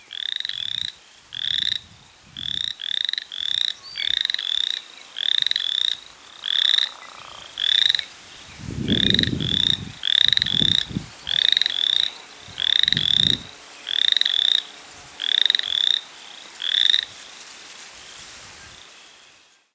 Sounds of Boreal Chorus Frog - Pseudacris maculata
The call of the Boreal Chorus Frog is a slow but short rasping noise rising in inflection and lasting 1/2 - 2 seconds. The sound is similar to stroking the small teeth of a pocket comb.
sound  This is a 19 second recording of two Boreal Chorus Frogs calling at a marsh in Manitoba, Canada shown to the right on a May afternoon, with a Northern Leopard Frog calling in the background.